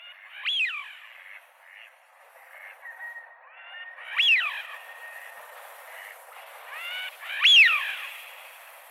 Sjöfåglarnas läten
Bläsand